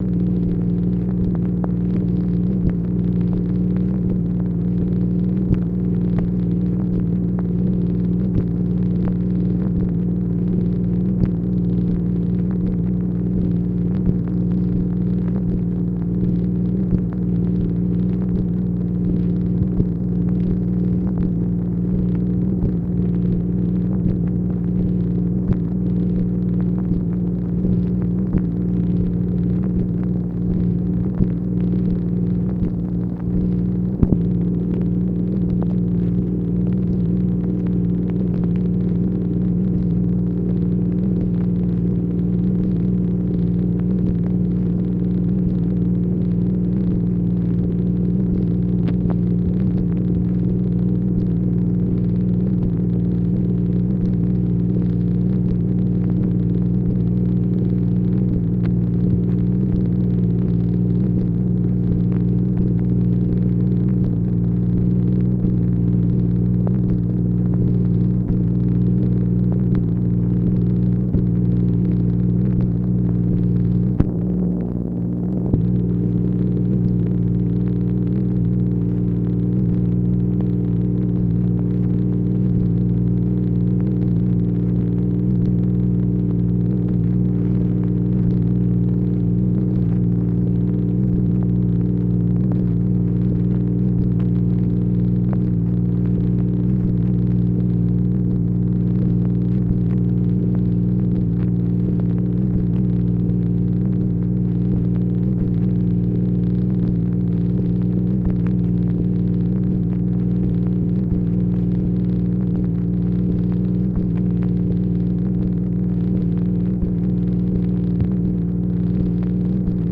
MACHINE NOISE, February 10, 1964
Secret White House Tapes | Lyndon B. Johnson Presidency